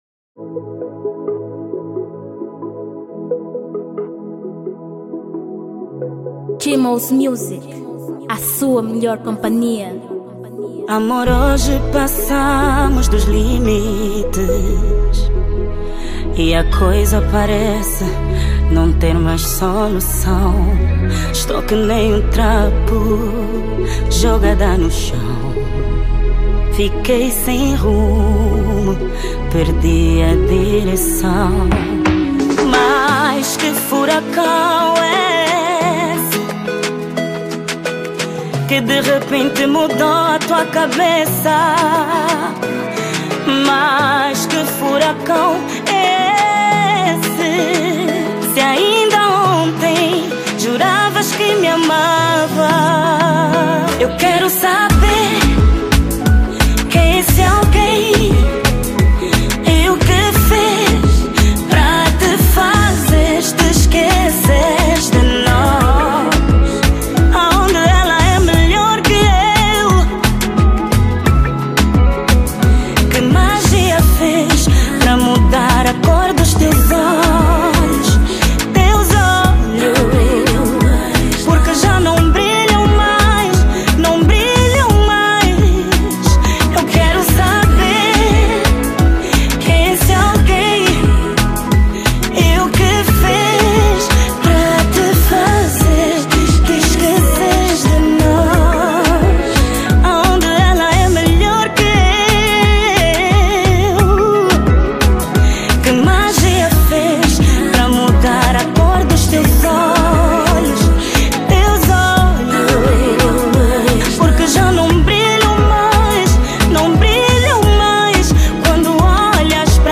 2023 Gênero: Kizomba Tamanho